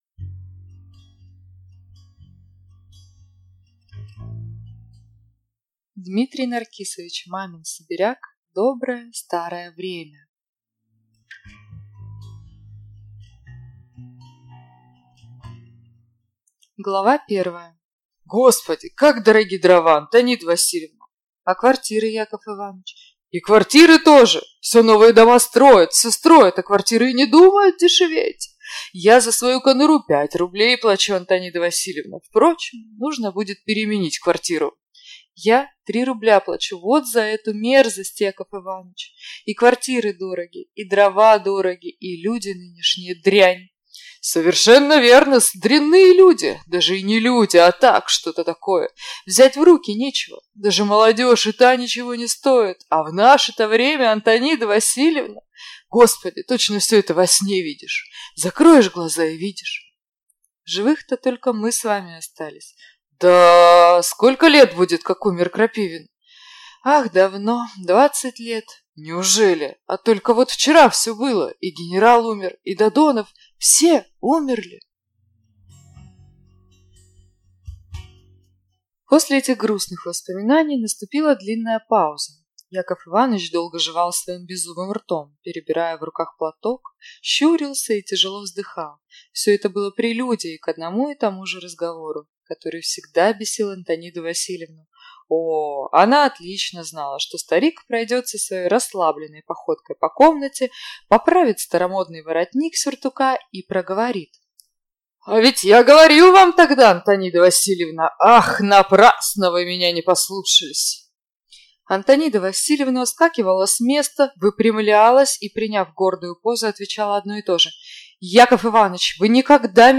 Аудиокнига Доброе старое время | Библиотека аудиокниг